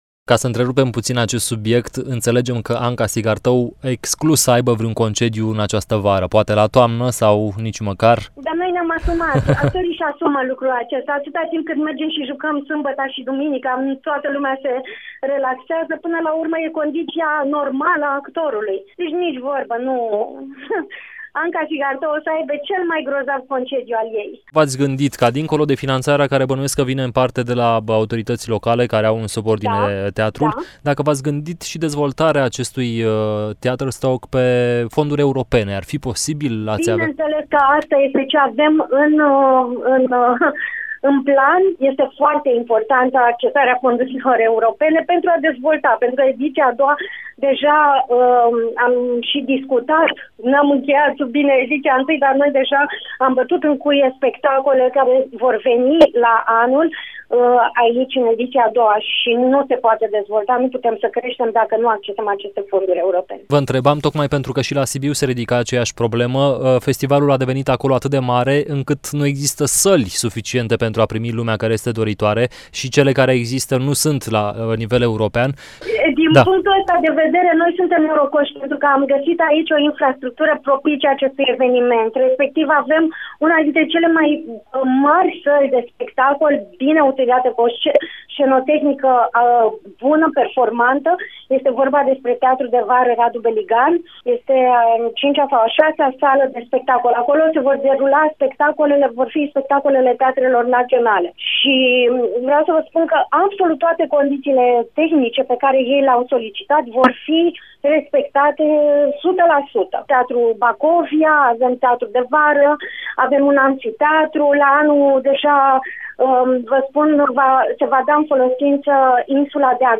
Într-un interviu acordat Europa Fm, explică de ce ”THEATERSTOCK” trebuie văzut în ideea ”WOODSTOCK” și cum a fost gândit acest nou eveniment dedicat teatrului: